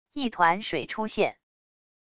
一团水出现wav音频